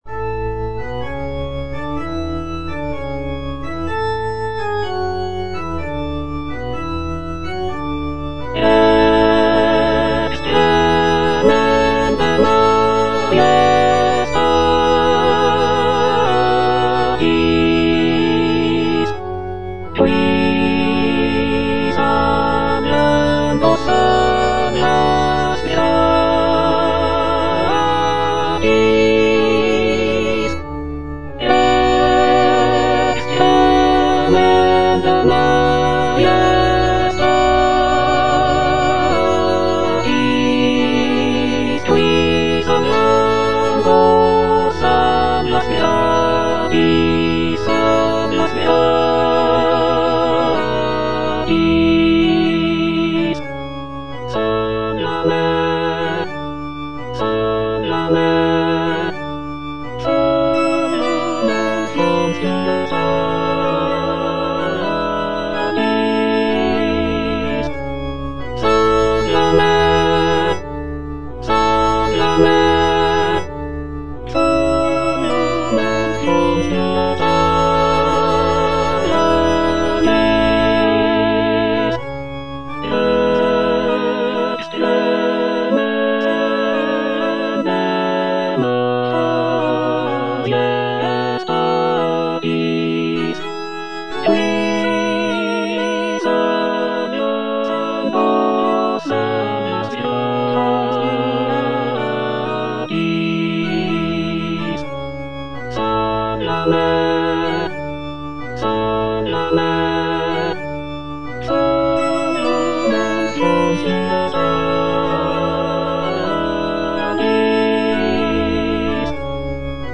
The piece features lush harmonies, soaring melodies, and powerful choral sections that evoke a sense of mourning and reverence.
F. VON SUPPÈ - MISSA PRO DEFUNCTIS/REQUIEM Rex tremendae - Alto (Emphasised voice and other voices) Ads stop: auto-stop